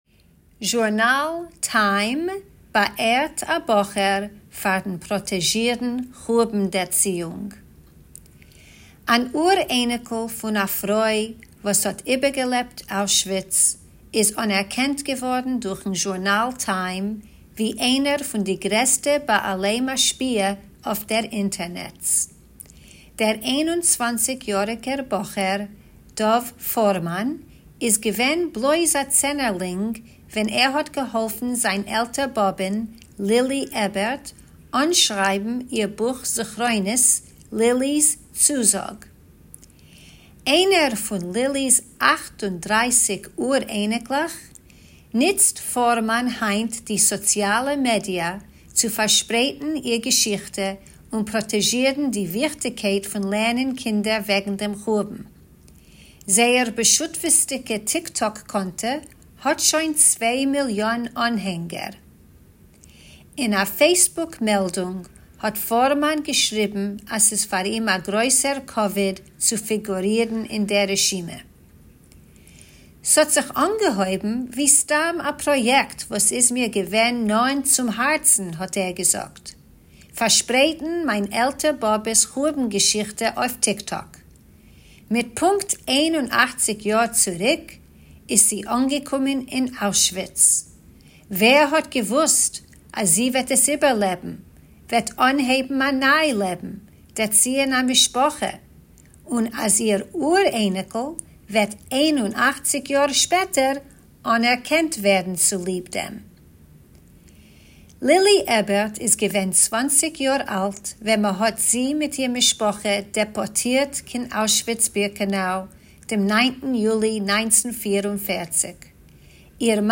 Tidbits is a Forverts feature of easy news briefs in Yiddish that you can listen to or read, or both!